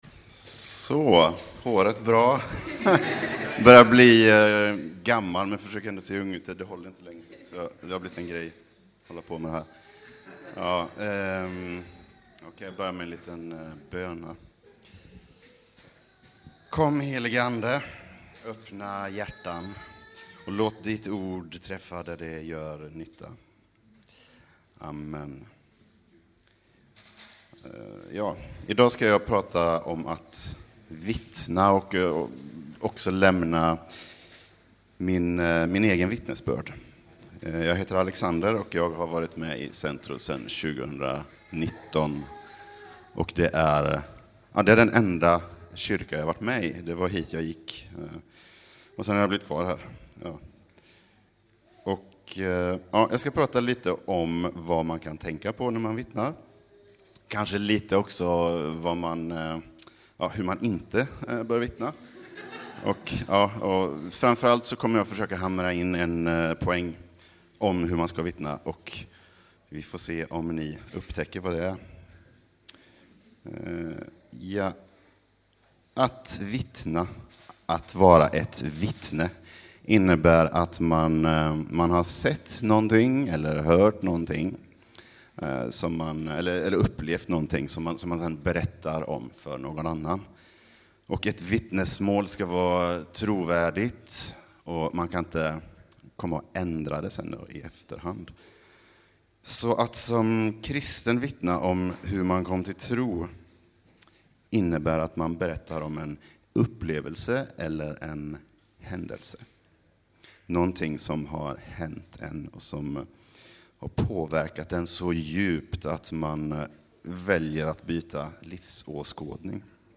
Vittnesbörd | Sermon Series | CENTRO
Vittnesbörd under en av våra sommarandakter.